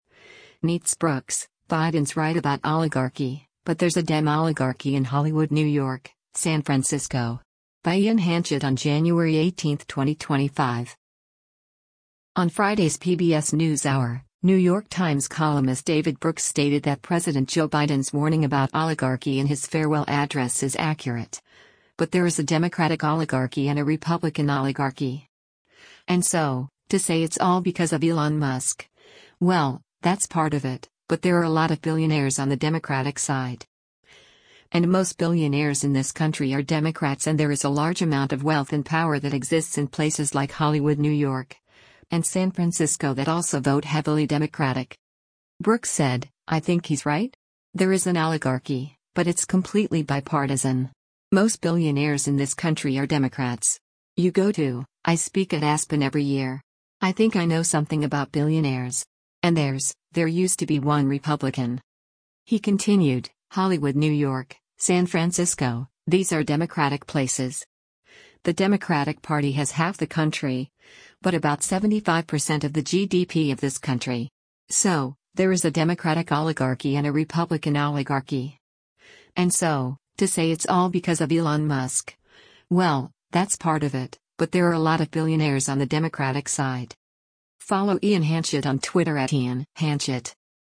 On Friday’s “PBS NewsHour,” New York Times columnist David Brooks stated that President Joe Biden’s warning about oligarchy in his Farewell Address is accurate, but “there is a Democratic oligarchy and a Republican oligarchy. And so, to say it’s all because of Elon Musk, well, that’s part of it, but there are a lot of billionaires on the Democratic side.”